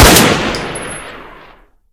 rogue_shoot.ogg